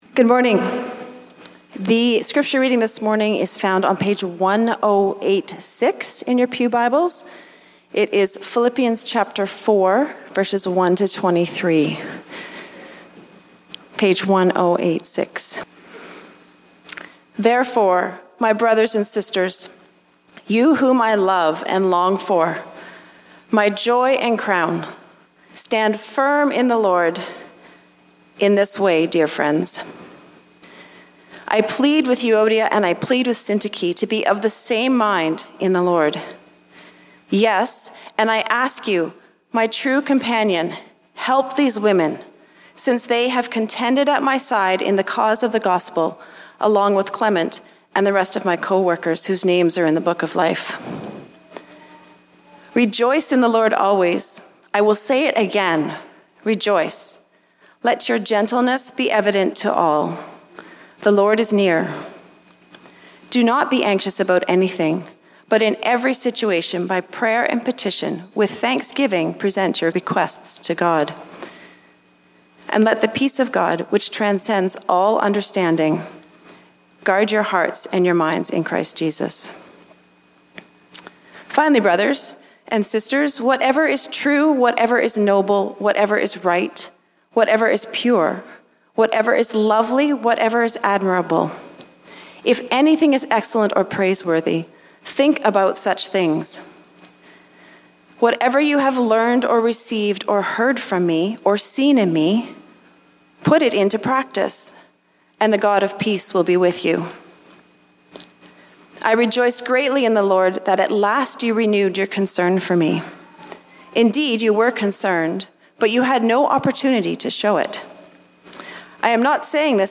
English Language Sermons | University Chapel